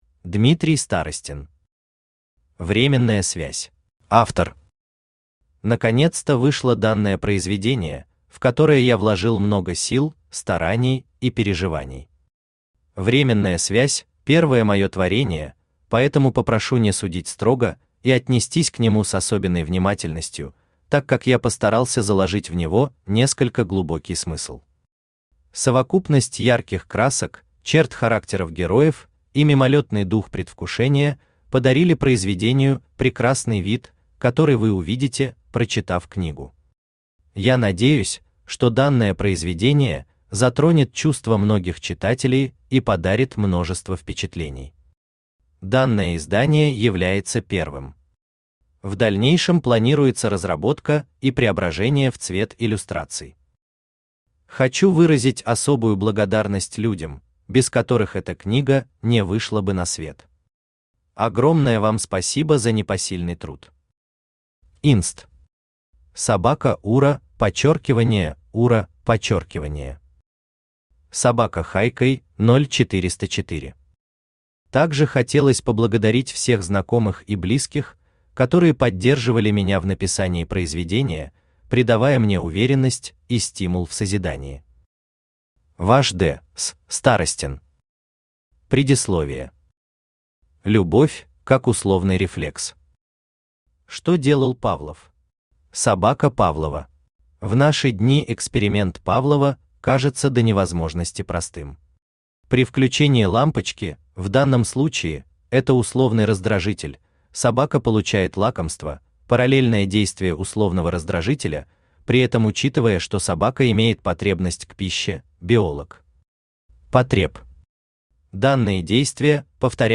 Аудиокнига Временная связь | Библиотека аудиокниг
Aудиокнига Временная связь Автор Дмитрий Сергеевич Старостин Читает аудиокнигу Авточтец ЛитРес.